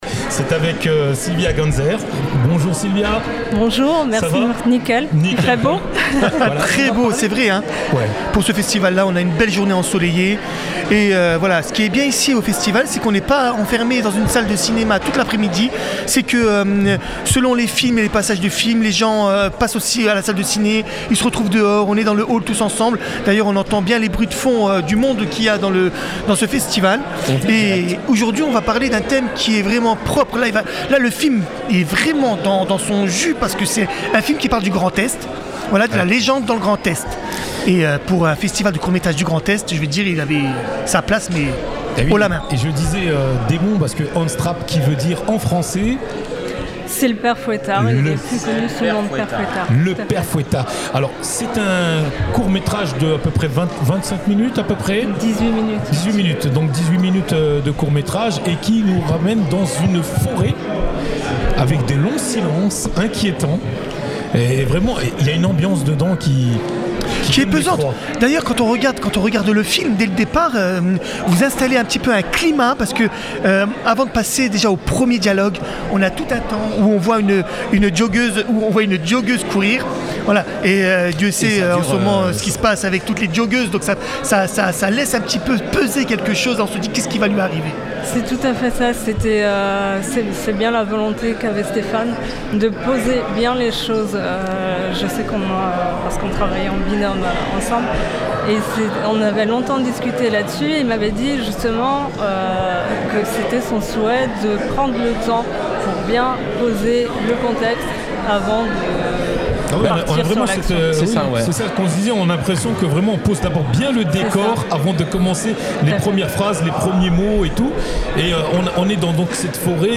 Boîte à images (Interviews 2025)